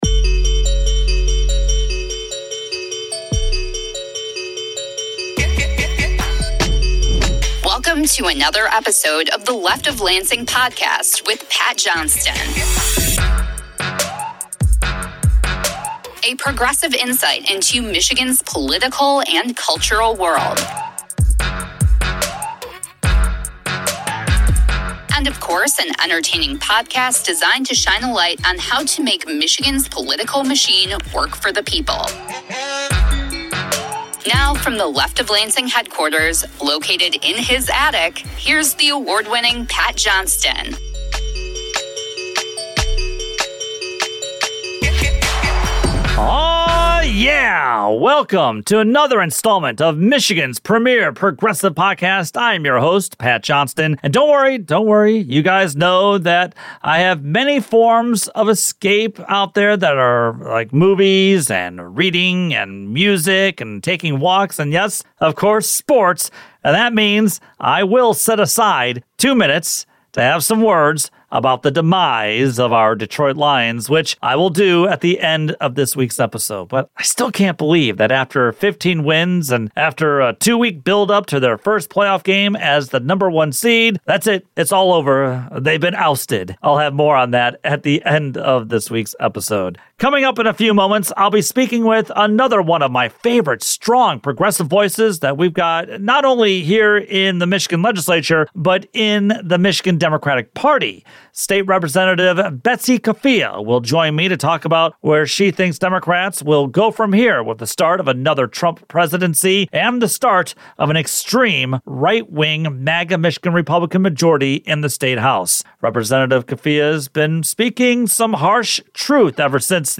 15:30-37:25: MI State Rep. Betsy Coffia Interview